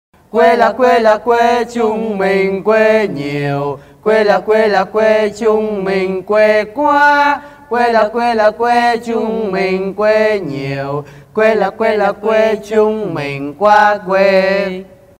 Thể loại: Câu nói Viral Việt Nam
cau-noi-que-la-que-la-que-chung-minh-que-nhieu-www_tiengdong_com.mp3